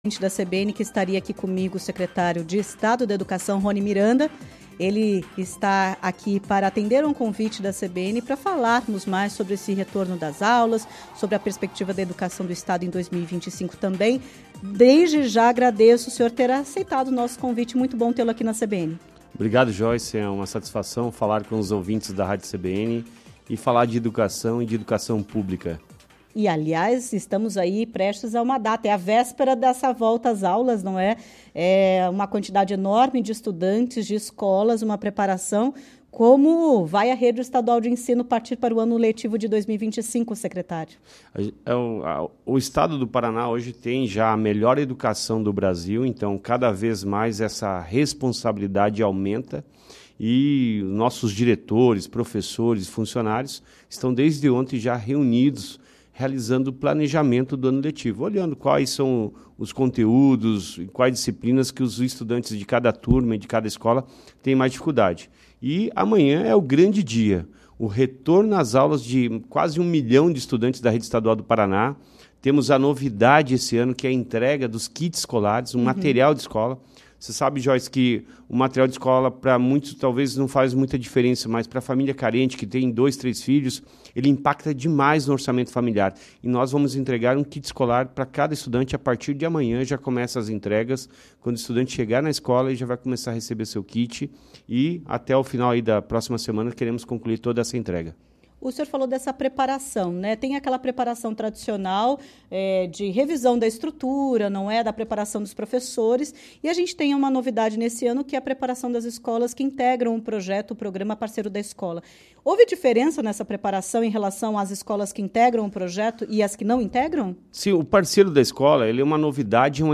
O secretário de Estado da Educação, Roni Miranda, esteve na CBN Curitiba nesta terça-feira (04)